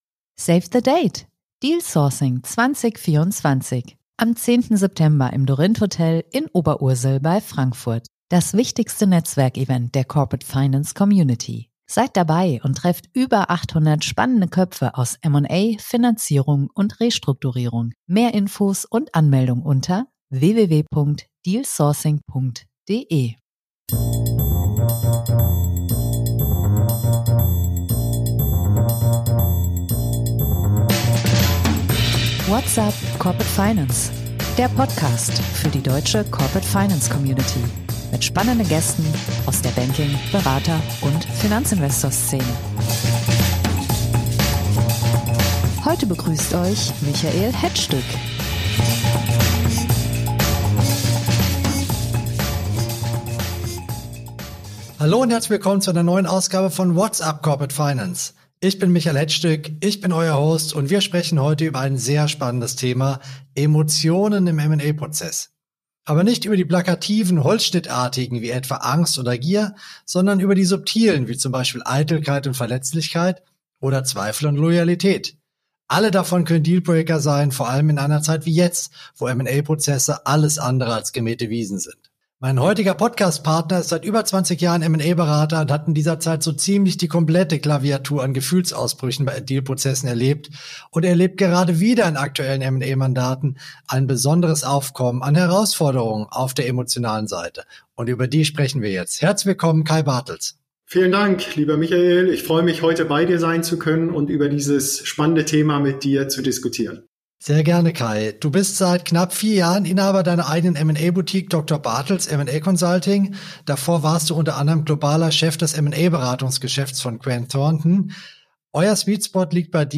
Und wie lassen sich die Emotionen dort in Schach halten? Das diskutieren wir in dieser Episode mit einem erfahrenen M&A-Berater.